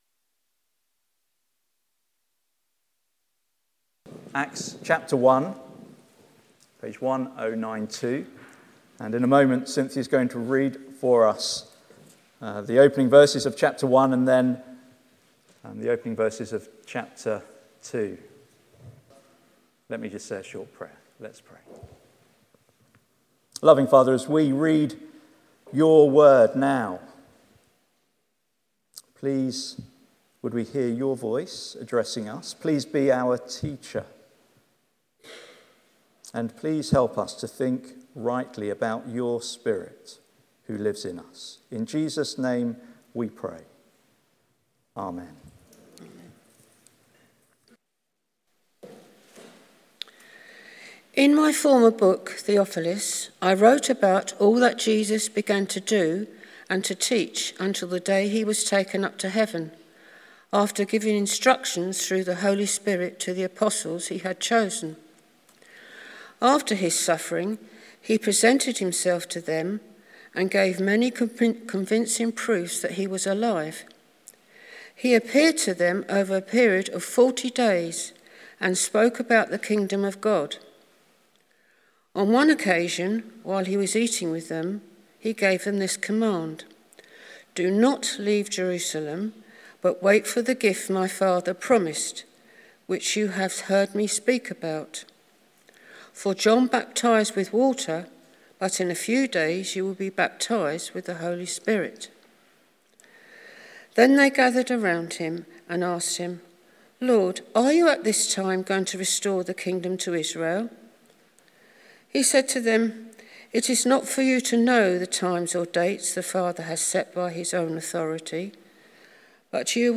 Passage: Acts 1:1-9, 2:1-4 Service Type: Sunday Morning Sermon Transcript Study Questions Topics